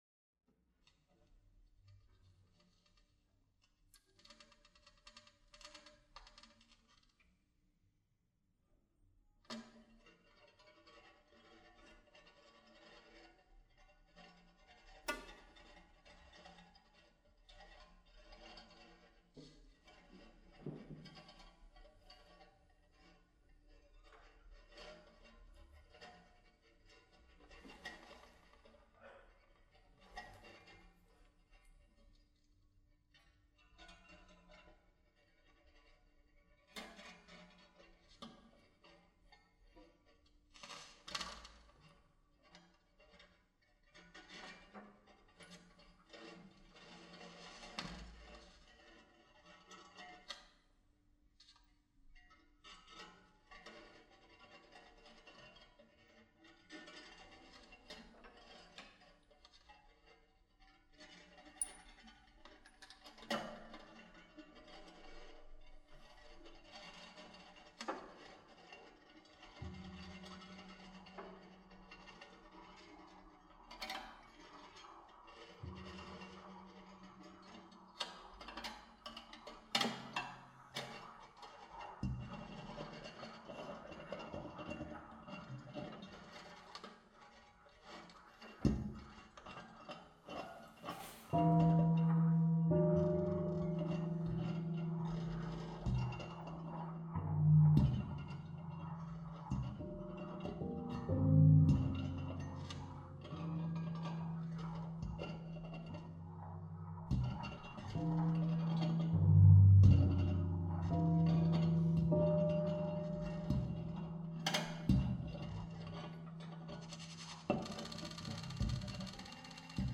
improvised and contemporary music
piano
percussion, electronics
Recorded in Dublin at the Goethe-Institut in November 2009